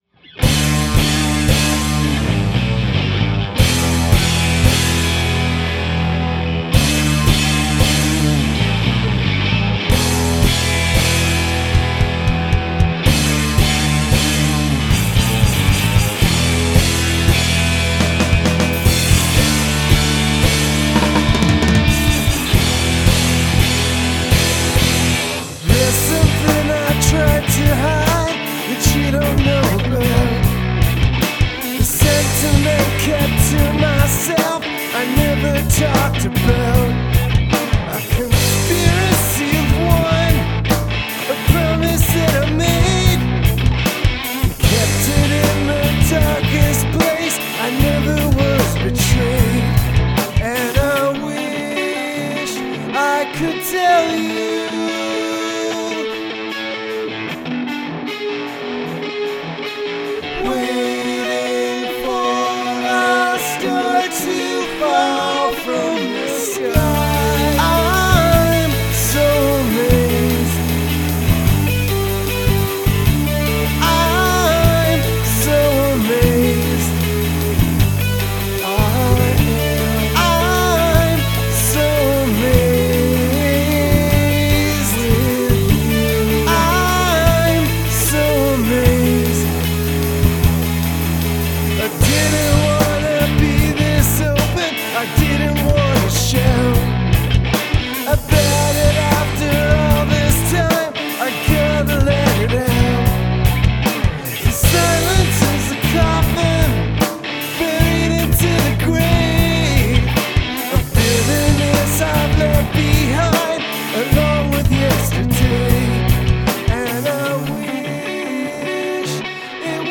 It's a sludge fest.
I may have overdid it pushing the top end: I figured the vox is the focus so I prioritized that. I thought the bright edgy guitars might get away with being sorta gitchy as compressed as the track is, it is too much though for sure.